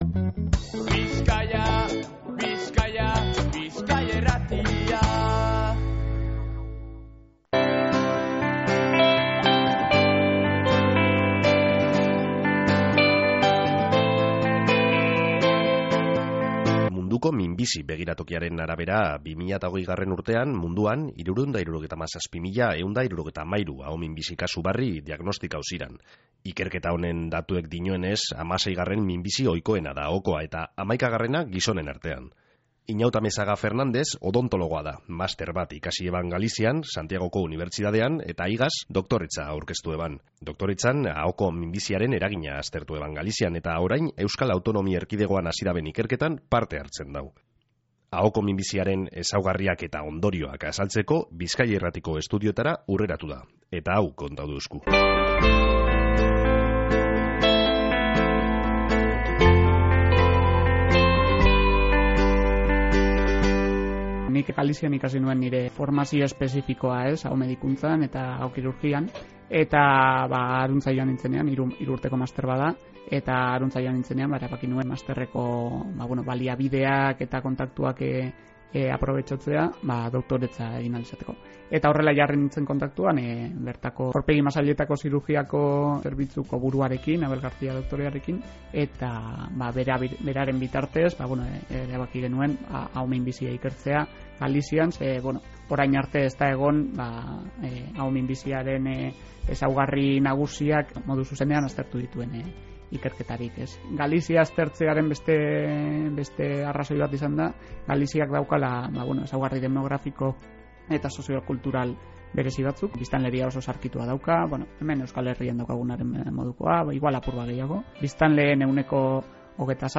AHOKO-MINBIZIA-ERREPORTAJEA.mp3